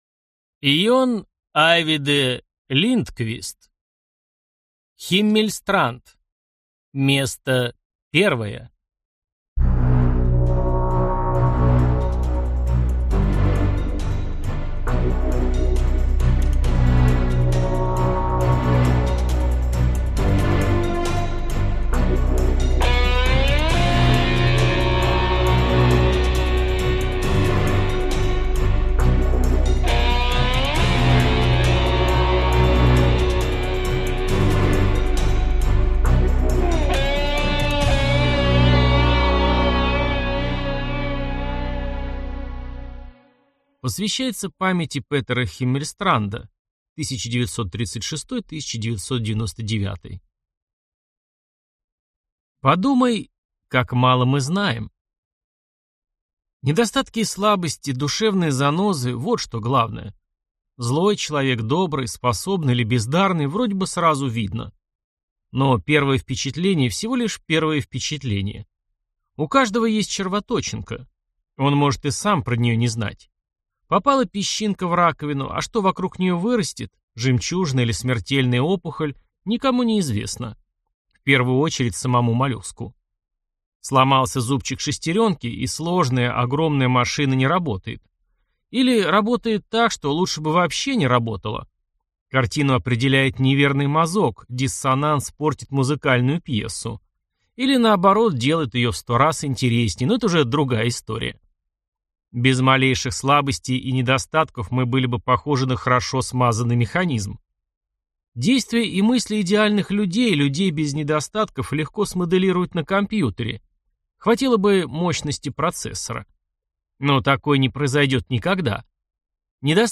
Аудиокнига Химмельстранд. Место первое - купить, скачать и слушать онлайн | КнигоПоиск